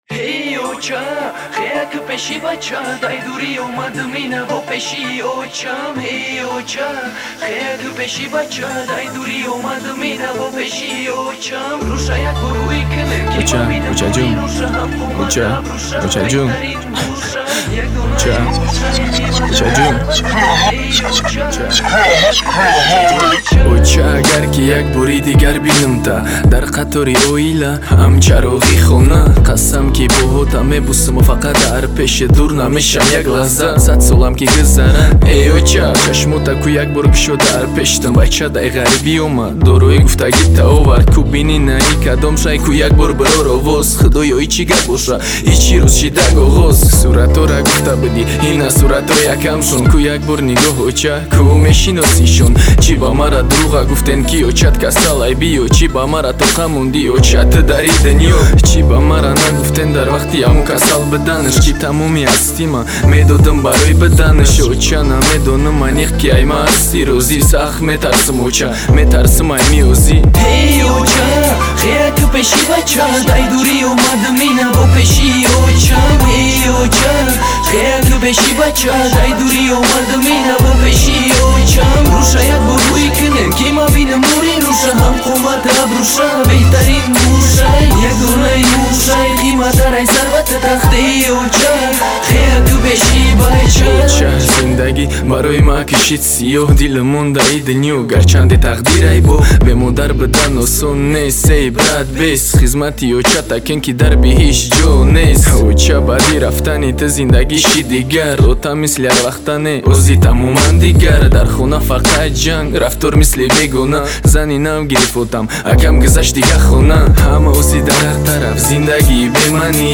Категория: Эстрада, Тадж. Rap